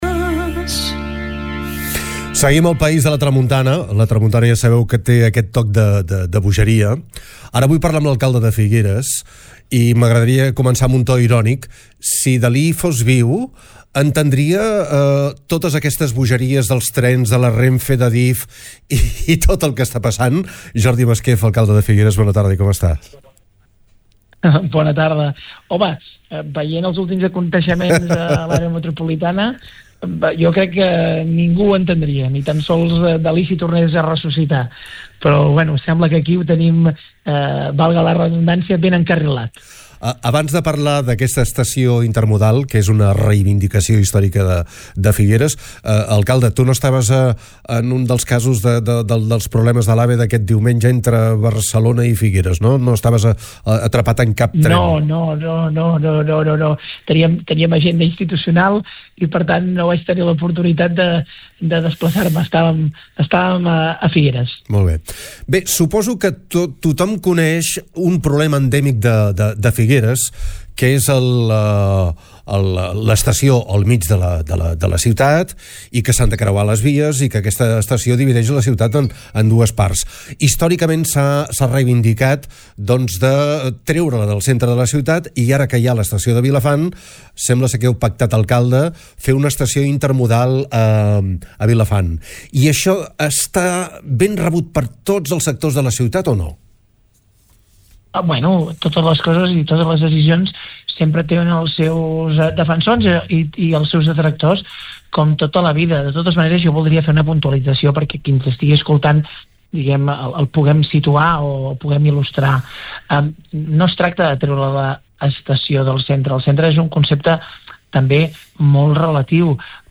En parlem a TARDA CAPITAL, amb l’alcalde de Figueres, Jordi Masquef.
alcalde-figueres.mp3